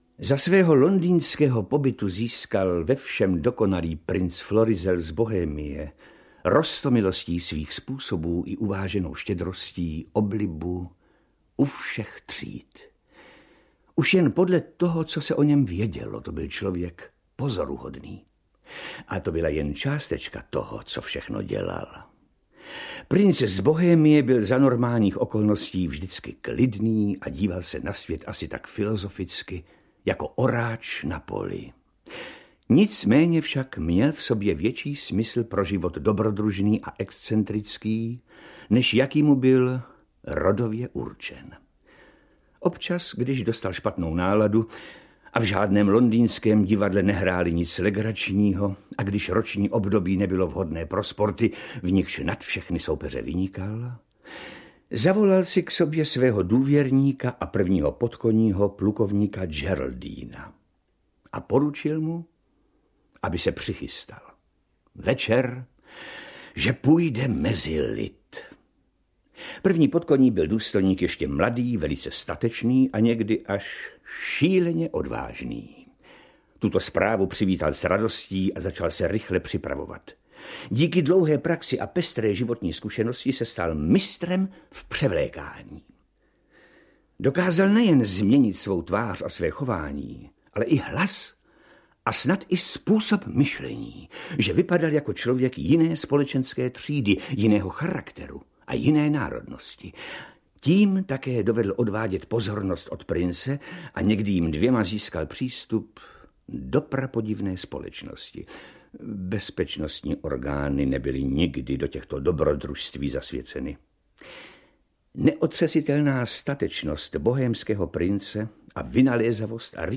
Klub sebevrahů audiokniha
Ukázka z knihy